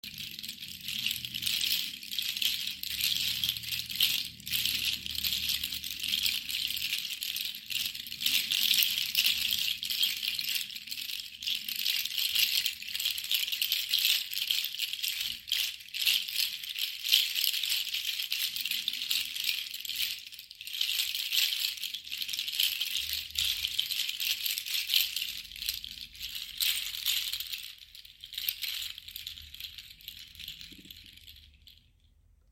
Seed Shaker Juju
• Handmade in Indonesia from tropical, dried Juju seeds
• Produces a warm sound, slightly louder than Pangi
Juju seeds are larger, louder, and produce a less soft tone compared to Pangi seeds, providing a distinct and powerful sound. The natural seeds create clear, resonant tones that add a unique texture to your music.